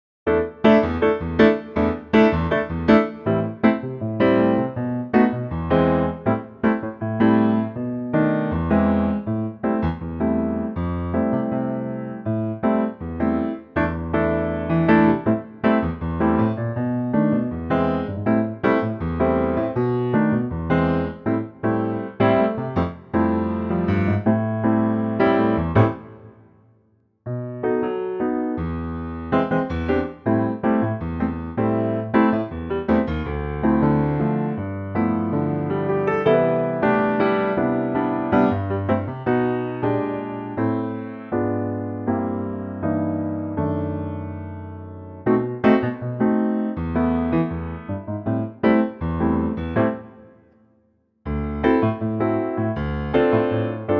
key - Am - vocal range - F# to A
Superb piano only arrangement